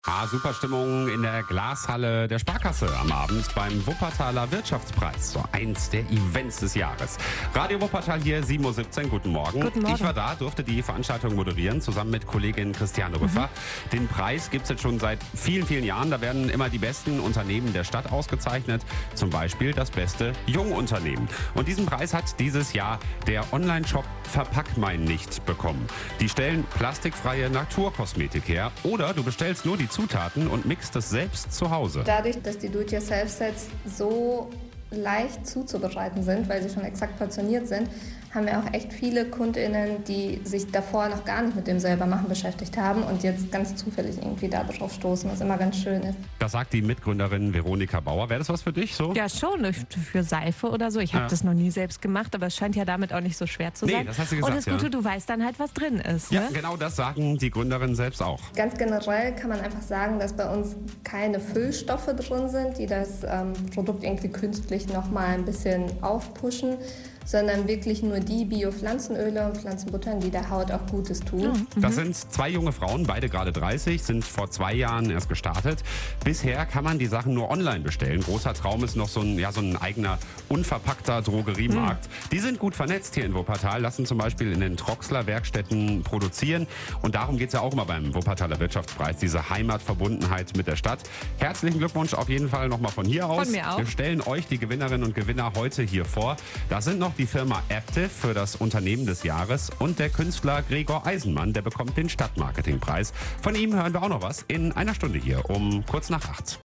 Am 27. Oktober 2021 wurden in der Glashalle der Sparkasse die Wuppertaler Wirtschaftspreise 2021 verliehen.
wuppertaler_wirtschaftspreis_mitschnitt_verpackmeinnicht_jungunternehmen.mp3